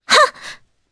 Laias-Vox_Attack1_jp.wav